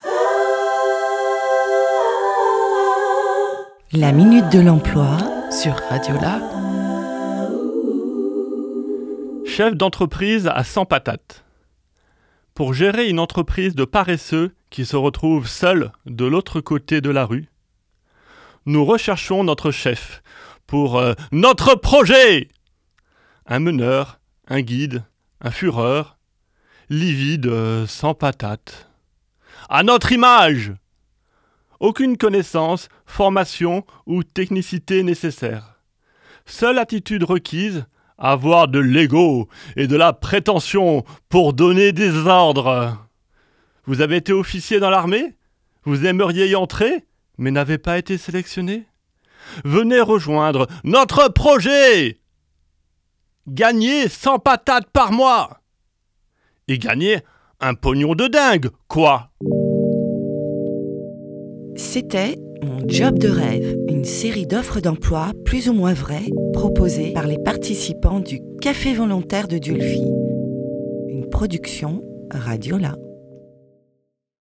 « Mon job de rêve » est une série d’offres d’emploi décalées, écrite et enregistrée par des participant-es du Café volontaire de Dieulefit en juillet 2024.